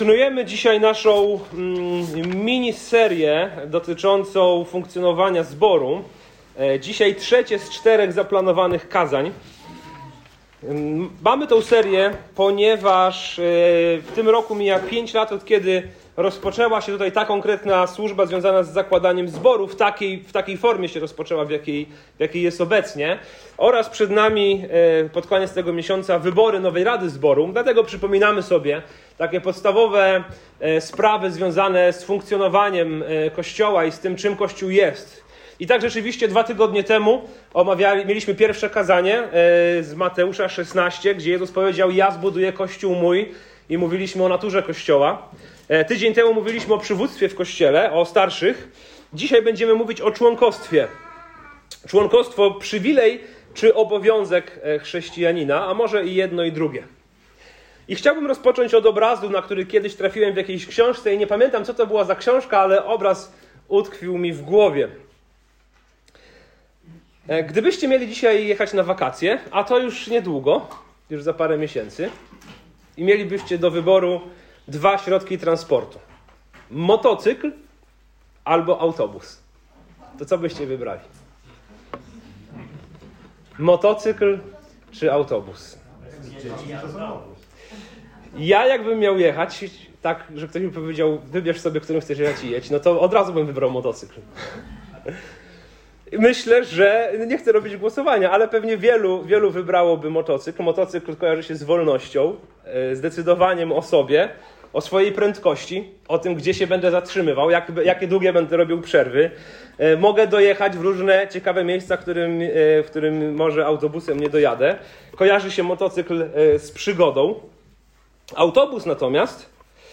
Wielu chrześcijan myśli o Kościele jak o miejscu, do którego czasem się zagląda. To kazanie pokazuje z Pisma, że członkostwo w zborze to coś znacznie głębszego – przymierze miłości, prawdy i odpowiedzialności.